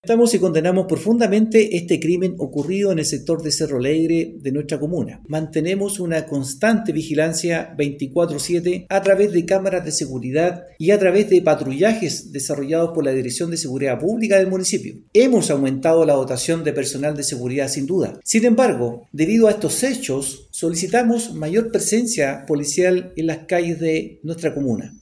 Por su parte, el alcalde de la comuna de Tomé, Ítalo Cáceres, lamentó el hecho y señaló que pese a aumentar los patrullajes preventivos, solicitan mayor presencia policial en la comuna.
cuna-alcalde-tome.mp3